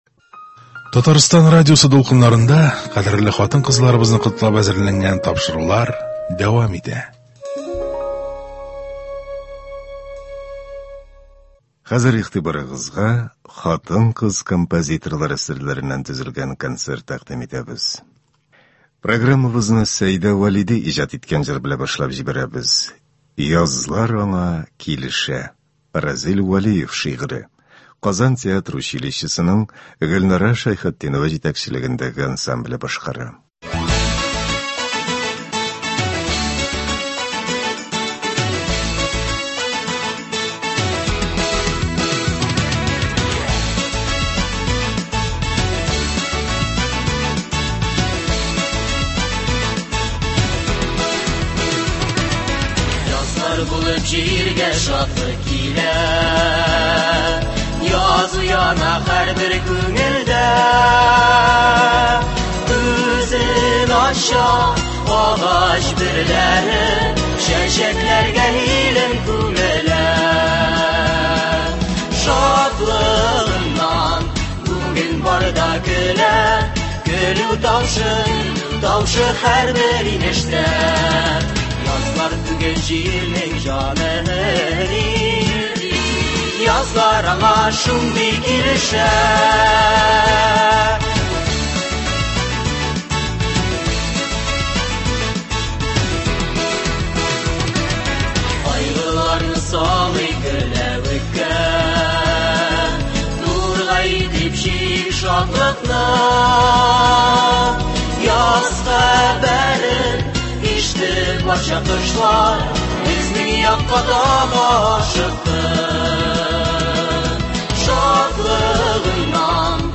Көндезге концерт. Бәйрәм концерты.